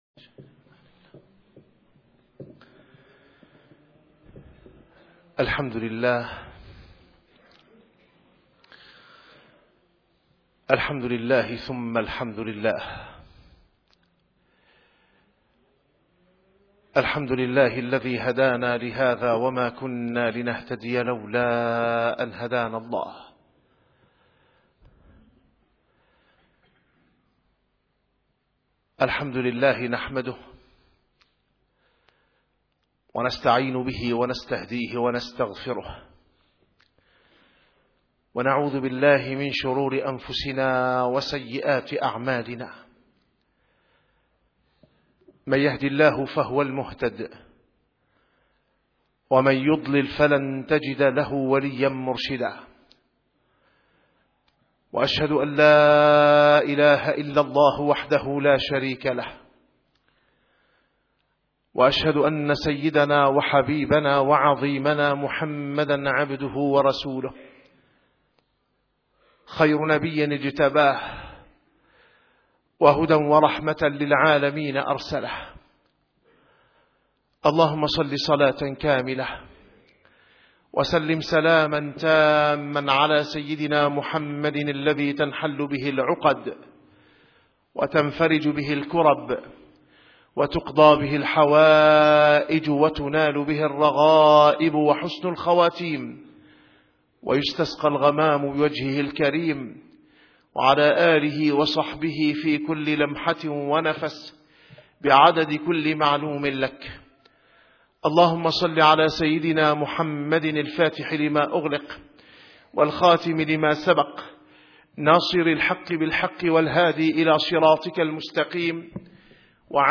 - الخطب - مستوى الشعور بالمسؤولية تجاه جيل الأمة